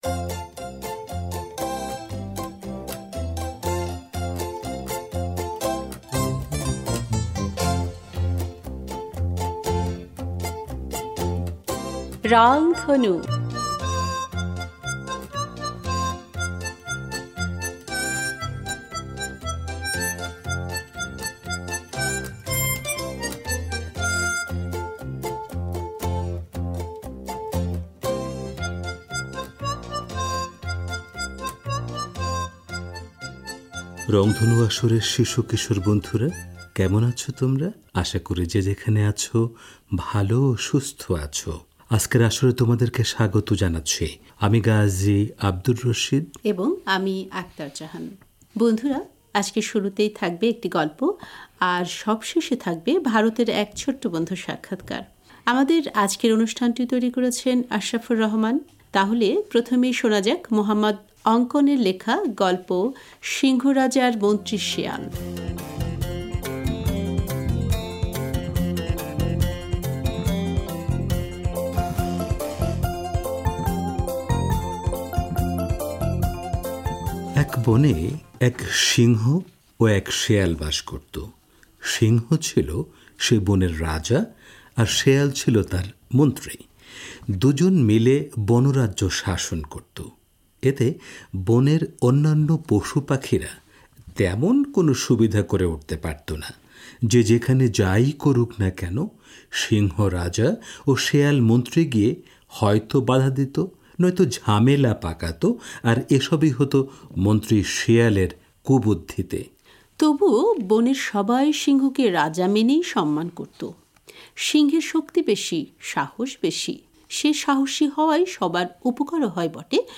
বন্ধুরা, আজকের শুরুতেই থাকবে একটি গল্প। গল্পের পর একটি গান।